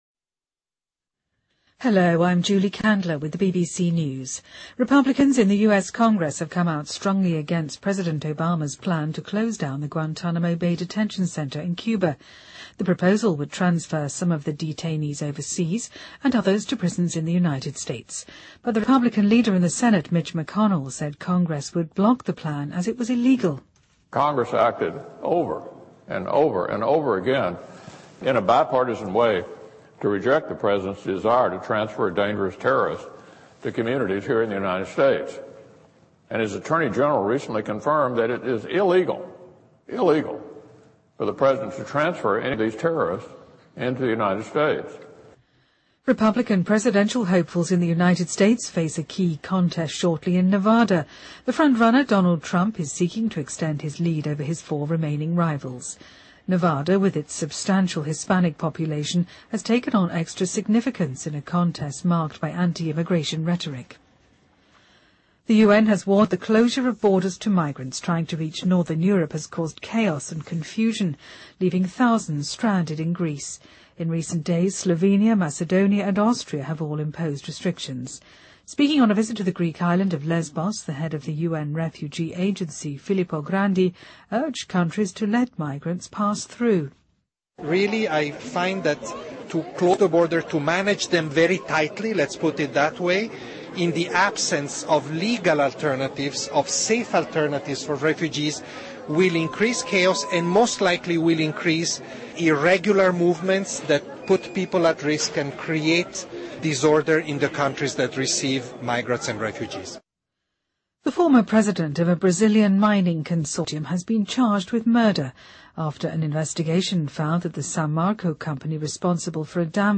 BBC news,美国新添14例寨卡疑似病例